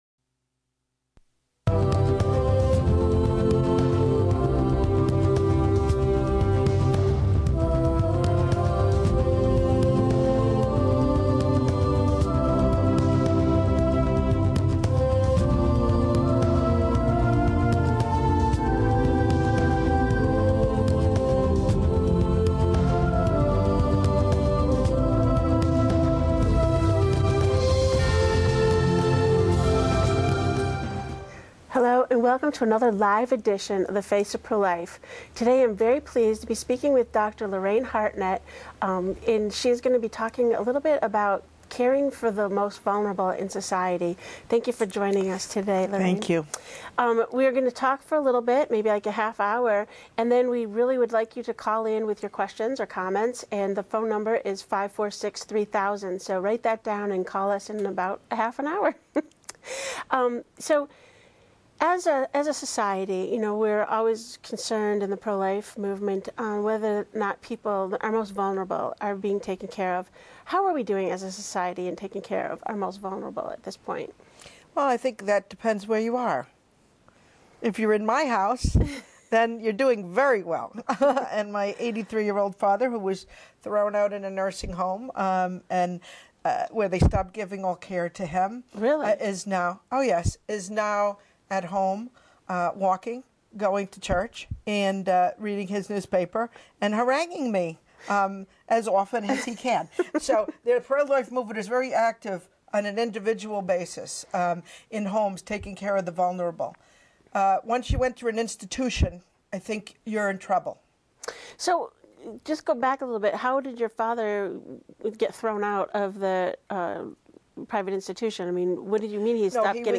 Face of Pro-Life #98: Live